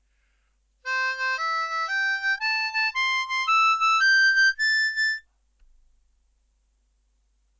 It’s the classic walking bass sequence.
Over the IV chord